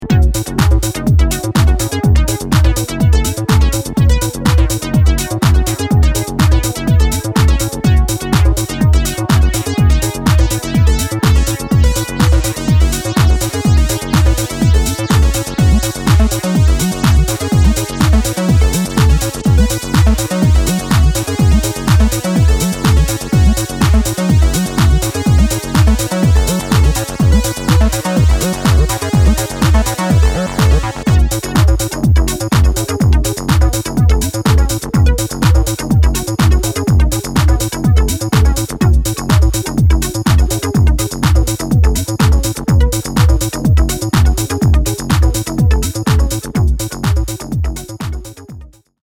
切れ味抜群のメロディーとアシッドの反復で深夜のフロアをより狂気的にデザインする
全体的にかなりソリッドにまとめられ